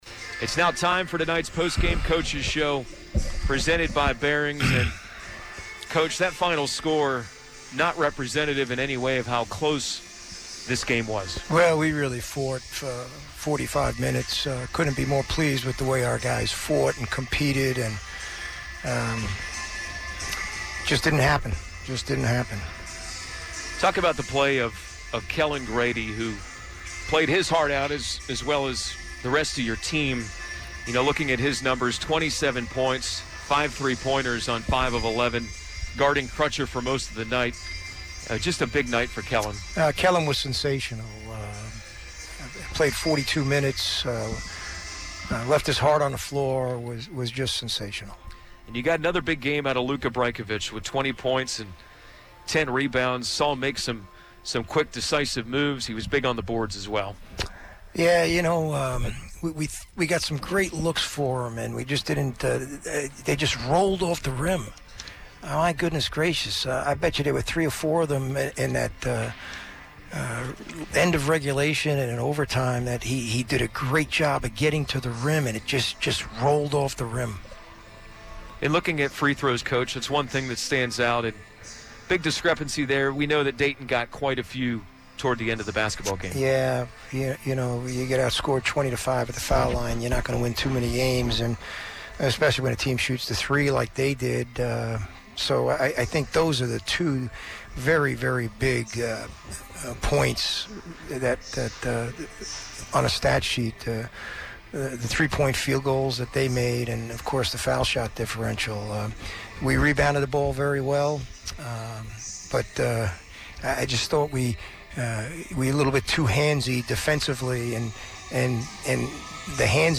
McKillop Postgame Interview